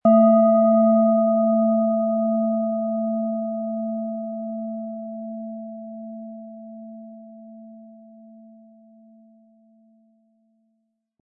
Planetenschale® Loslassen im Kopf & Tief in Entspannung fallen lassen mit Thetawellen, Ø 10,9 cm, 100-180 Gramm inkl. Klöppel
Im Sound-Player - Jetzt reinhören können Sie den Original-Ton genau dieser Schale anhören.
Mit Klöppel, den Sie umsonst erhalten, er lässt die Planeten-Klangschale Thetawelle voll und harmonisch erklingen.